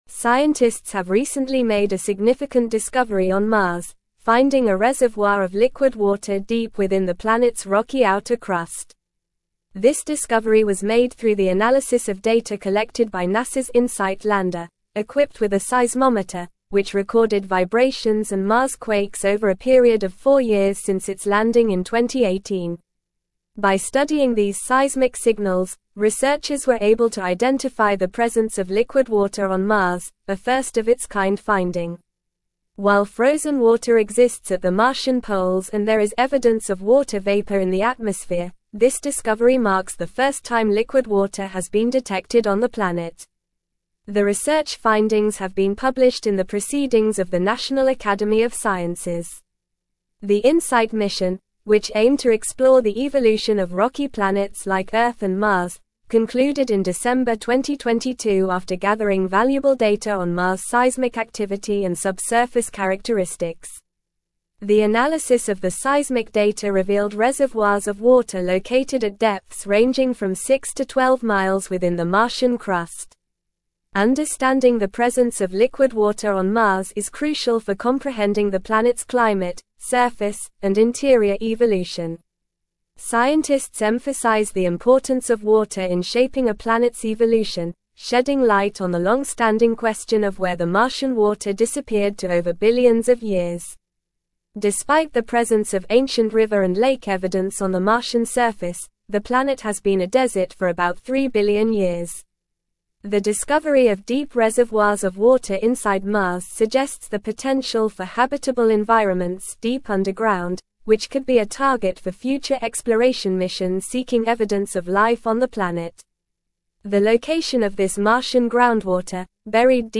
Normal
English-Newsroom-Advanced-NORMAL-Reading-Discovery-of-Liquid-Water-Reservoir-on-Mars-Revealed.mp3